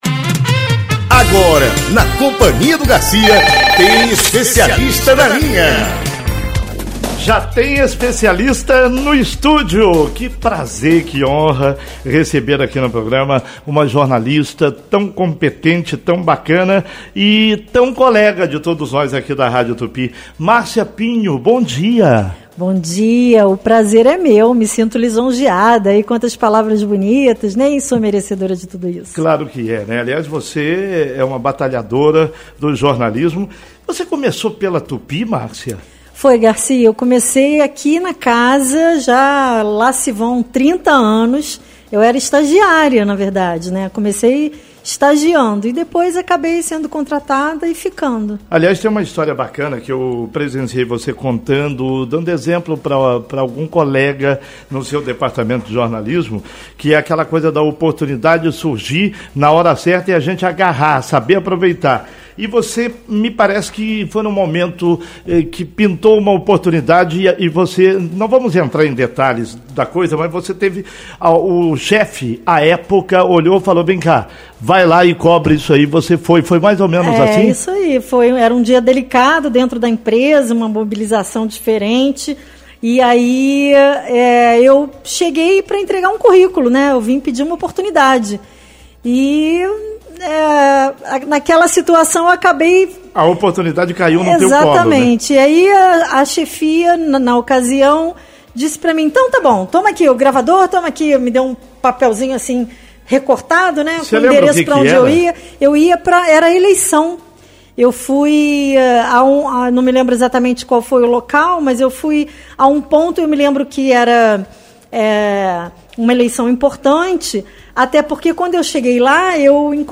Confira a entrevista completa abaixo: